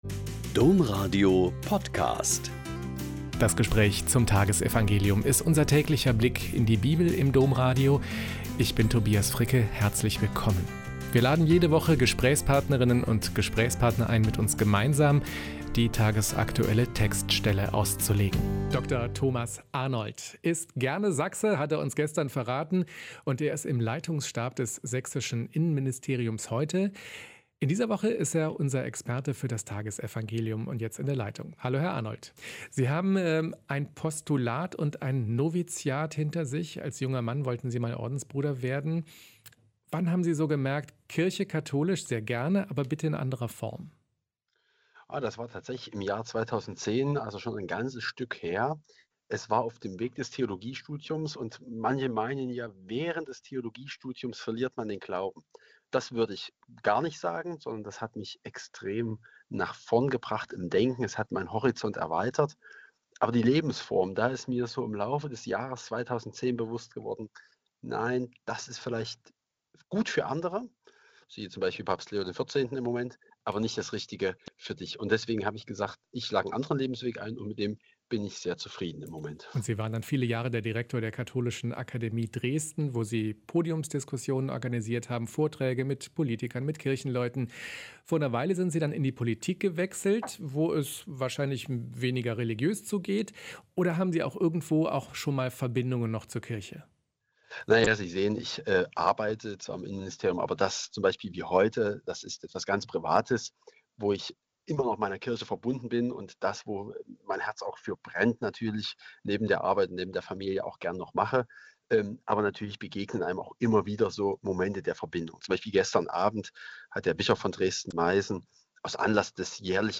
Mt 6,1-6.16-18 - Gespräch